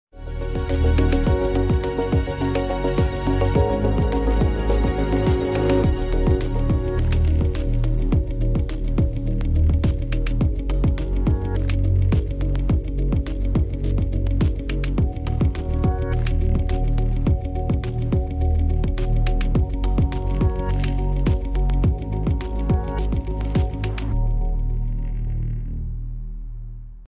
CleanForce Garlic Press Ginger Crusher sound effects free download